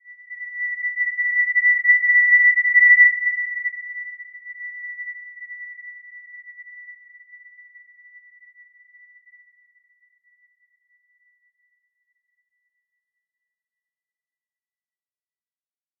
Simple-Glow-B6-mf.wav